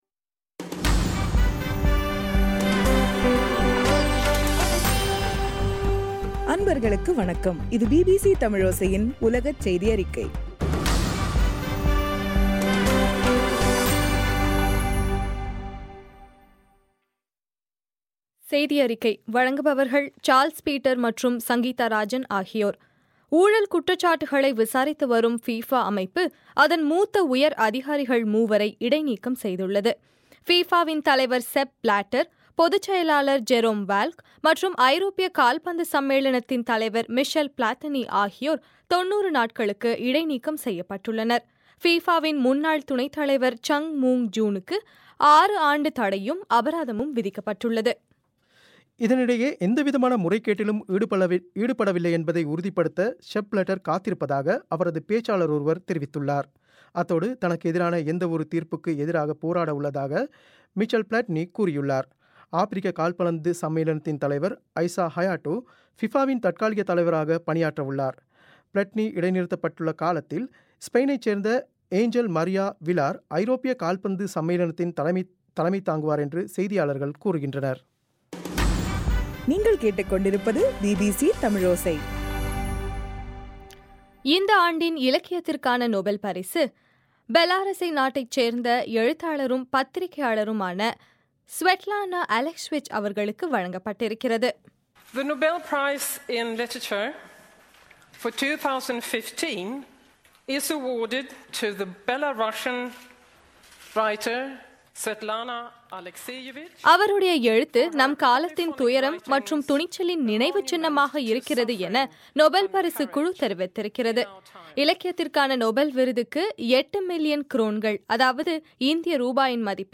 பிபிசி தமிழோசையின் உலகச் செய்தியறிக்கை